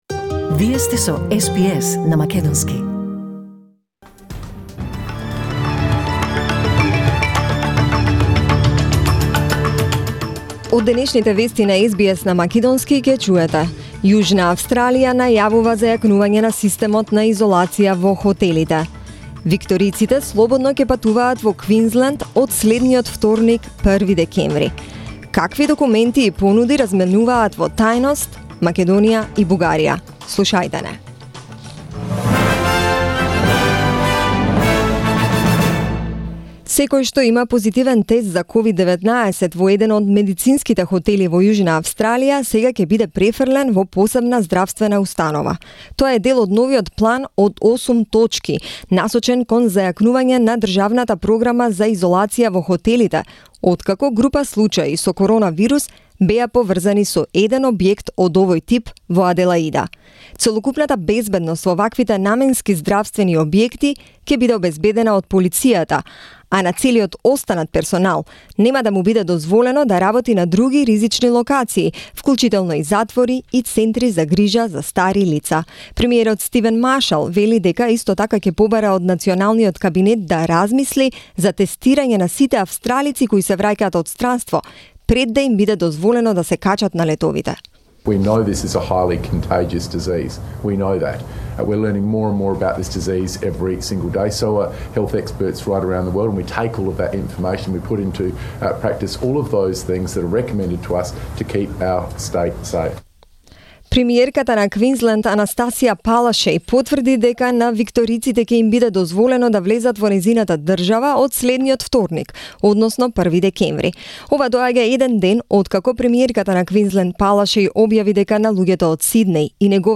SBS News in Macedonian 25 November 2020